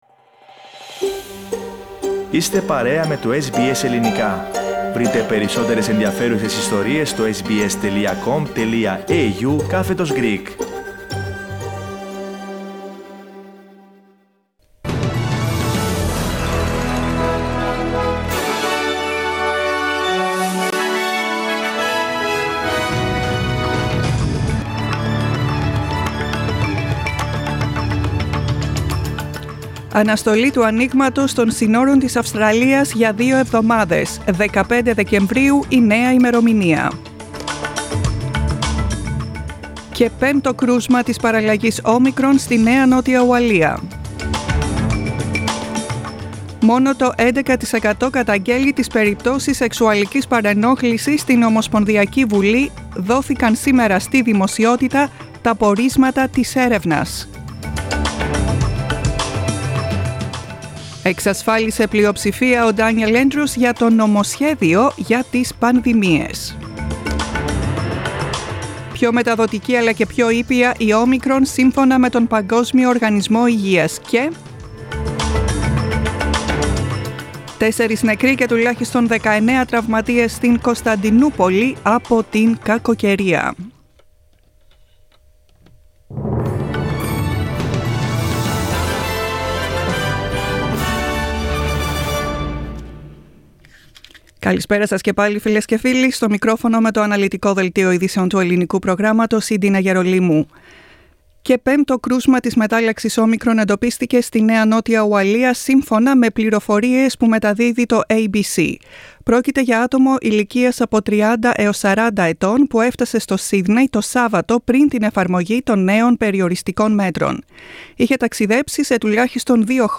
News bulletin in Greek, 30.11.21